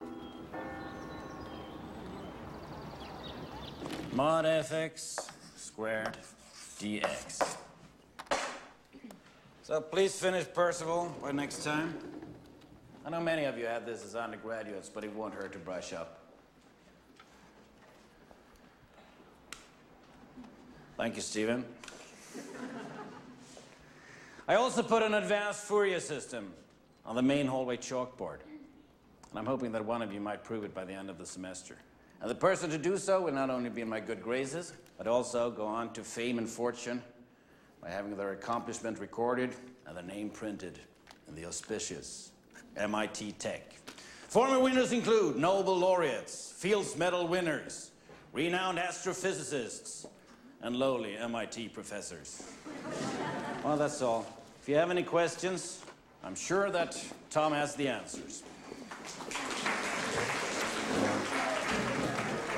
On this page, I have only 5 sequence examples taken from actual movies.
The end of a linear algebra lecture with the announcement of a contest.